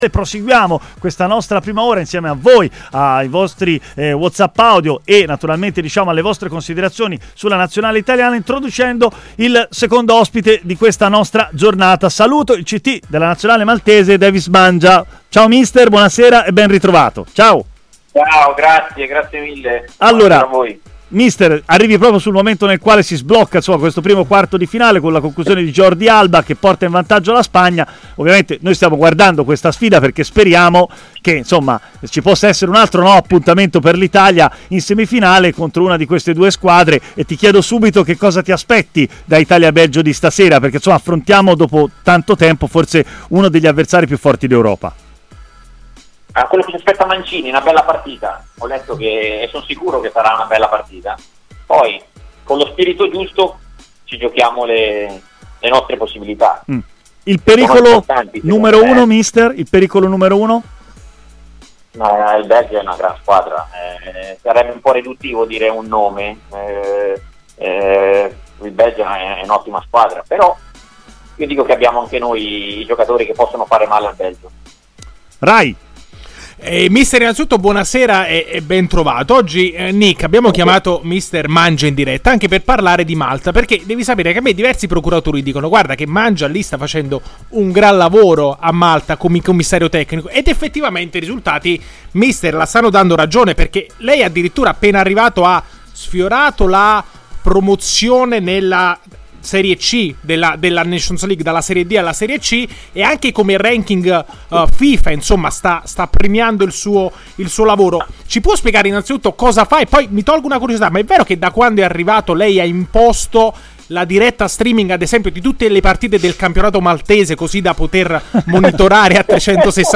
ha così parlato a Stadio Aperto, trasmissione di TMW Radio